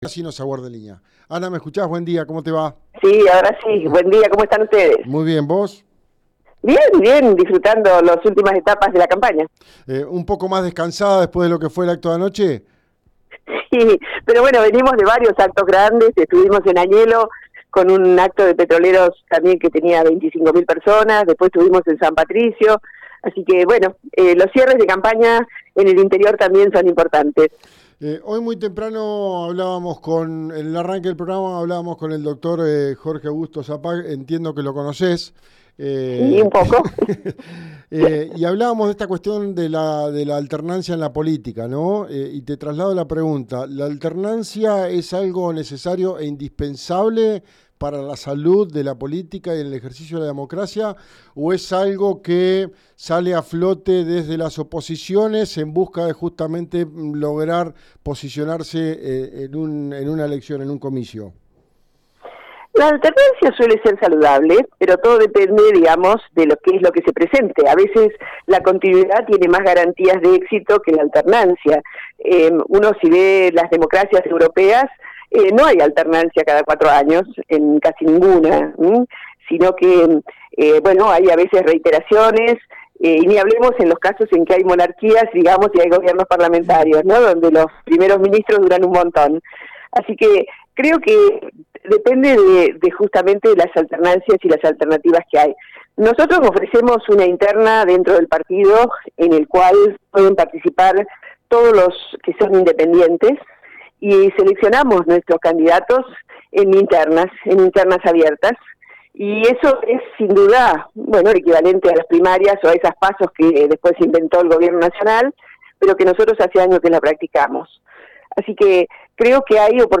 Entrevista completa: